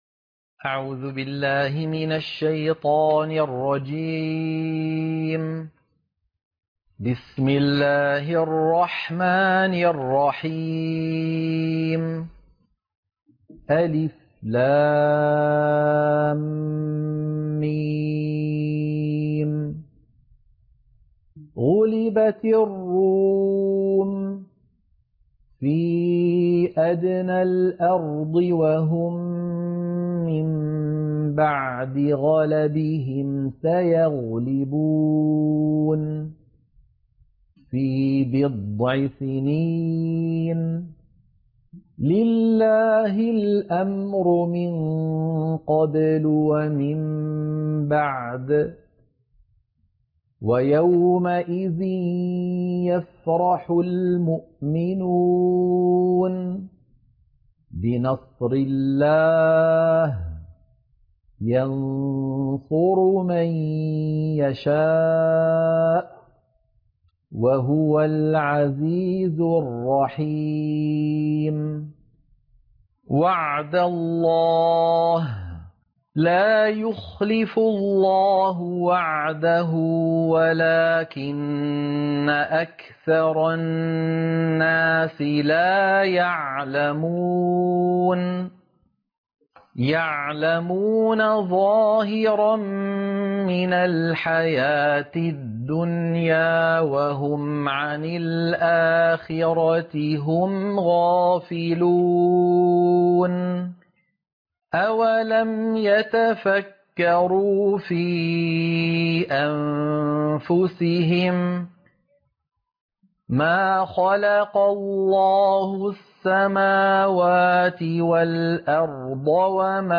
سورة الروم - القراءة المنهجية